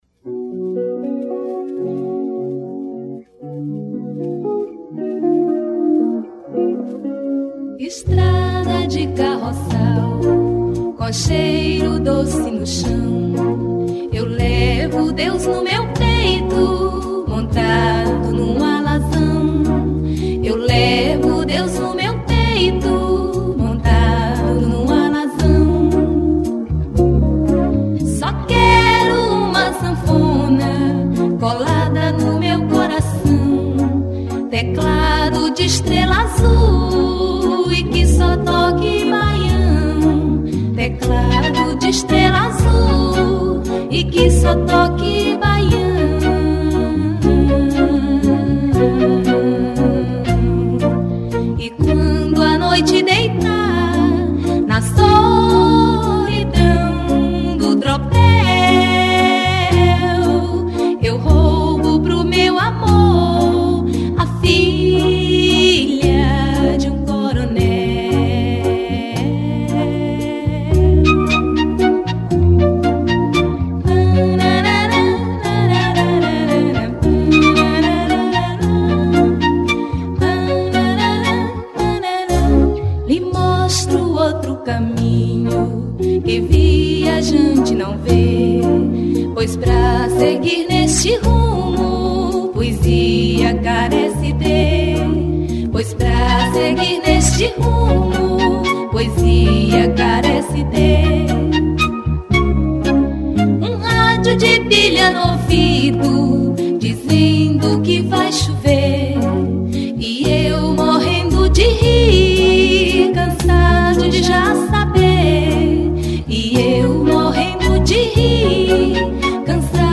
101      Faixa:     Mpb